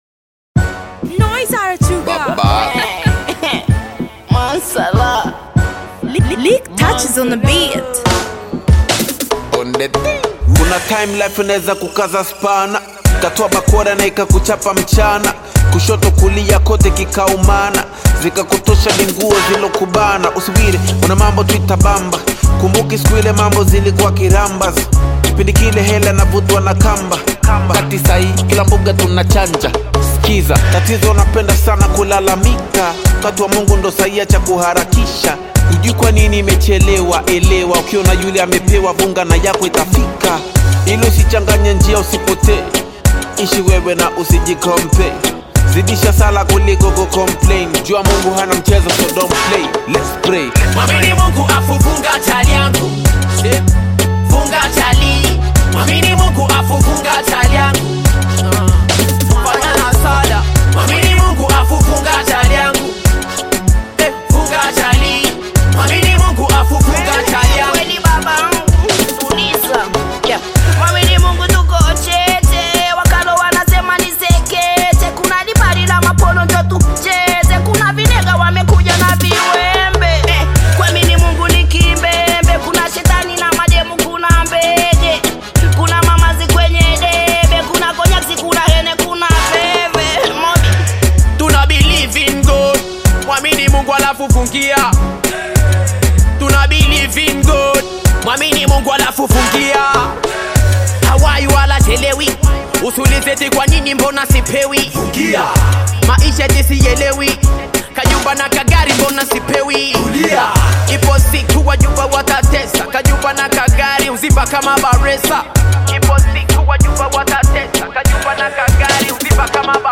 Tanzanian bongo flava hip-hop
African Music